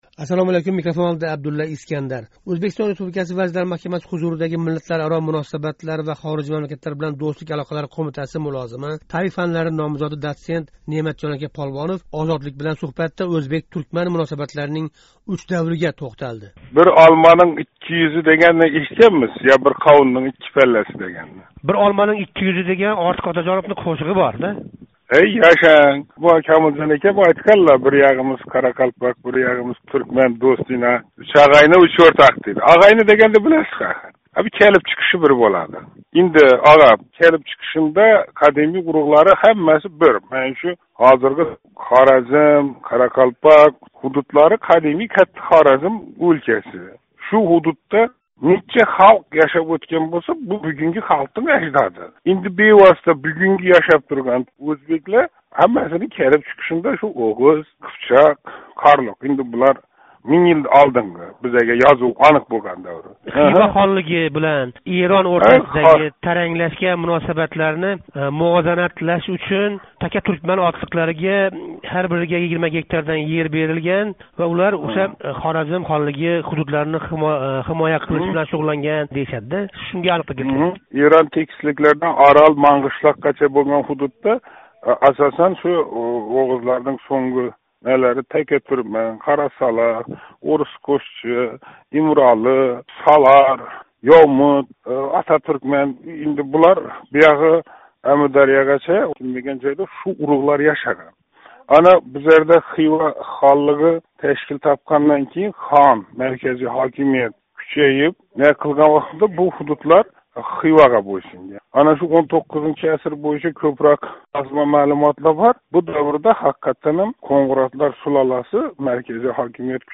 Озодлик билан суҳбатда ўзбек-туркман муносабатлари яхши томонга ўзгарганидан мамнунлигини билдирди.